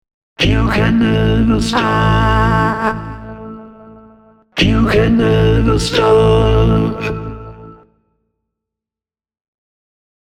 Alle Soundeffekte in den Hörbeispielen, wie Distortion, Chorus und andere, wurden mit den On-Board-Effekten von VocalSynth 2 erzeugt.
Für mein Hörbeispiel habe ich das Wort „stop“ einmal in Richtung A getunet (st-a-a-a-p) und einmal nach O.
03_VocalSynth2_vowelcontrol.mp3